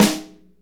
Index of /90_sSampleCDs/Northstar - Drumscapes Roland/SNR_Snares 1/SNR_Motown Snrsx
SNR MTWN 08L.wav